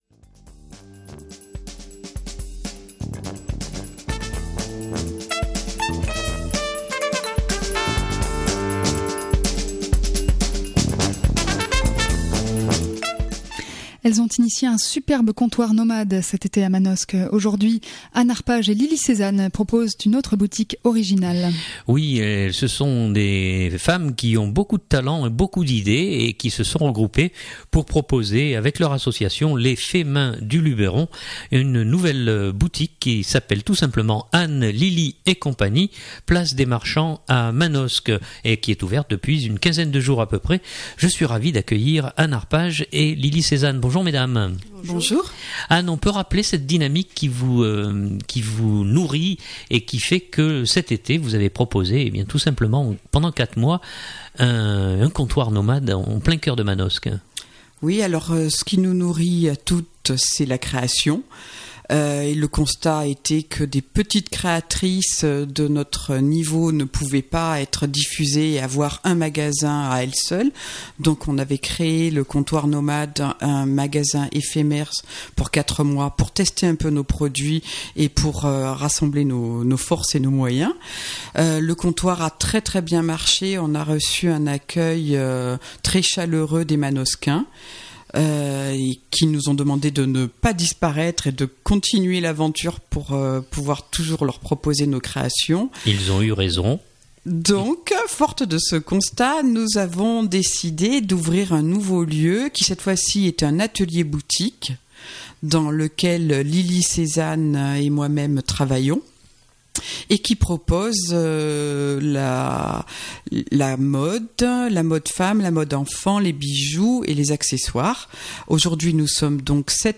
Elles sont nos invitées.